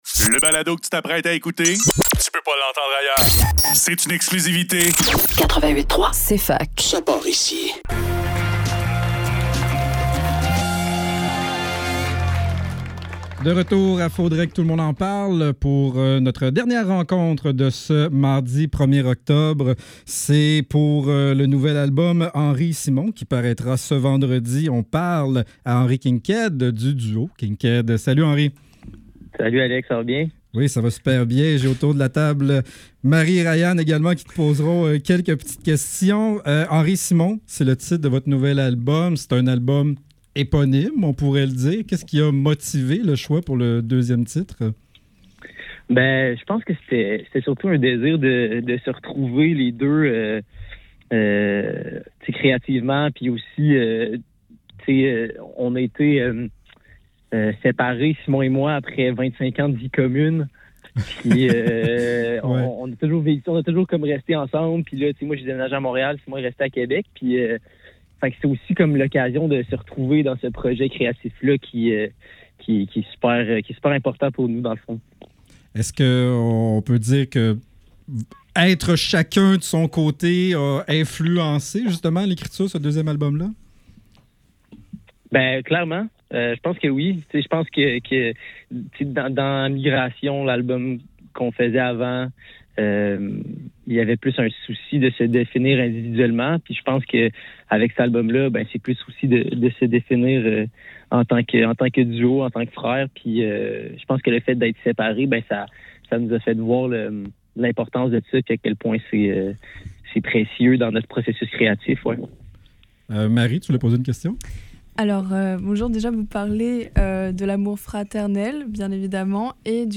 Entrevue avec